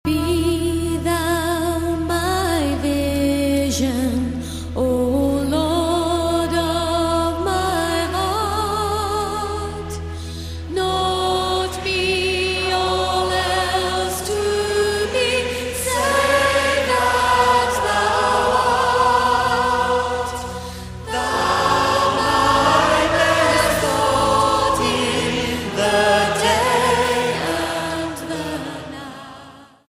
STYLE: MOR / Soft Pop
A good mix, well presented, with quite a Celtic flavour.